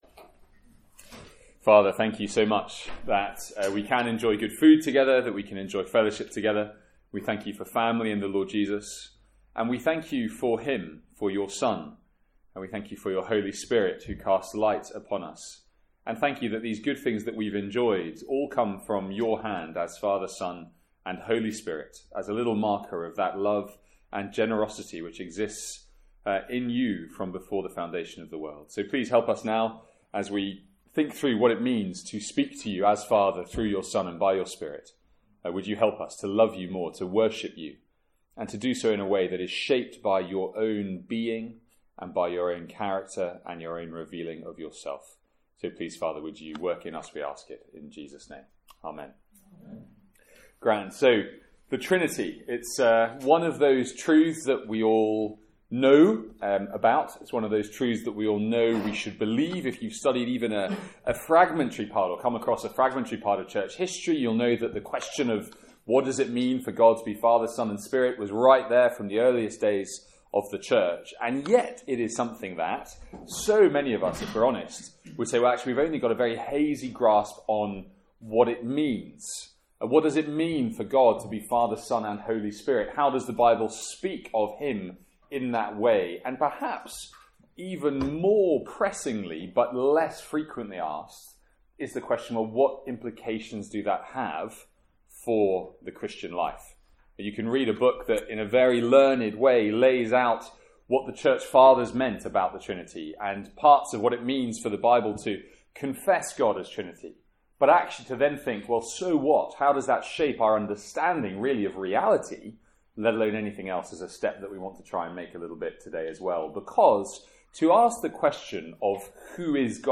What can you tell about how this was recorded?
From our fourth Student Lunch of the academic year.